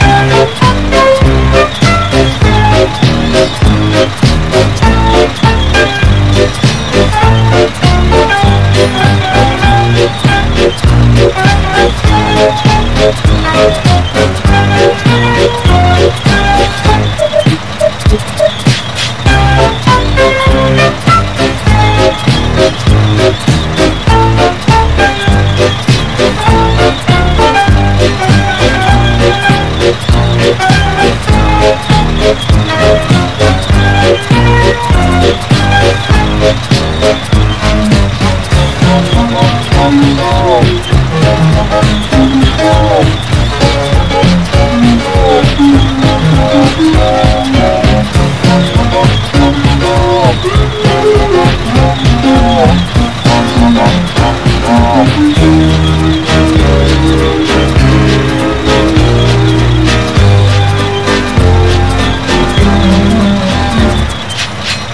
mvm_tank_loop.wav